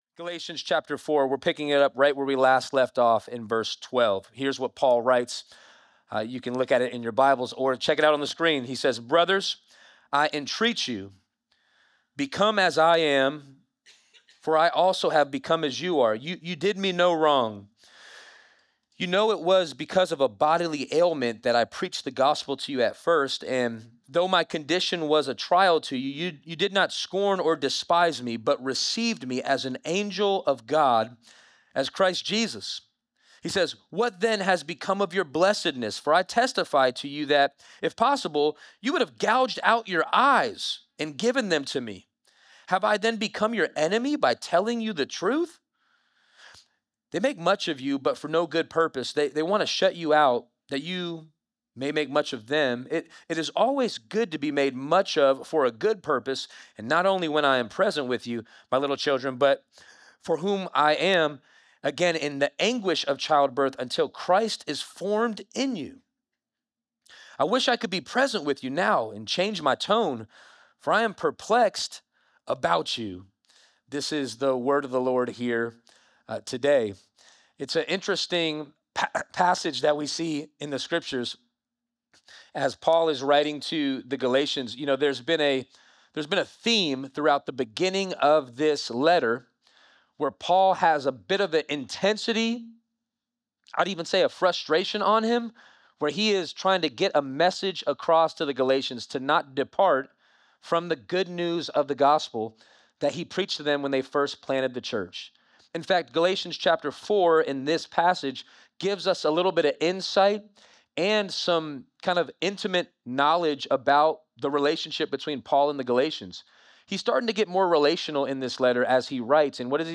Sermons podcast of Walk Church in Las Vegas, NV